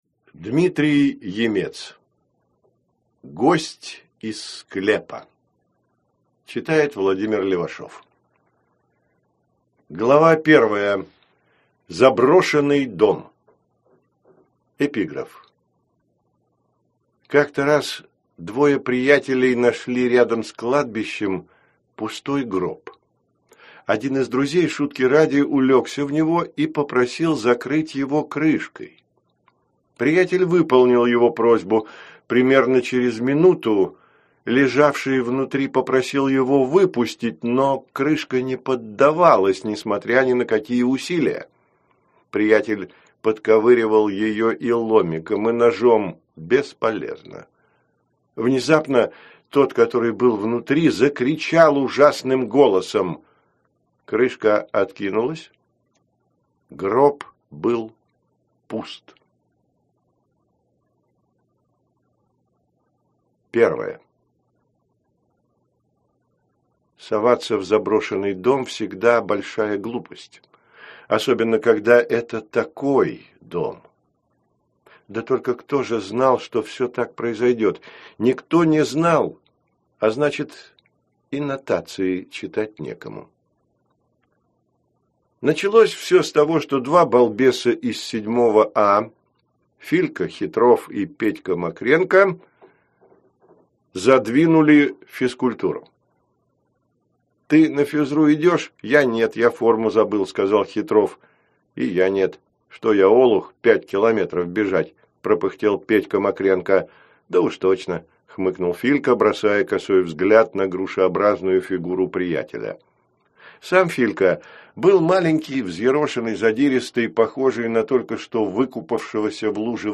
Аудиокнига Гость из склепа | Библиотека аудиокниг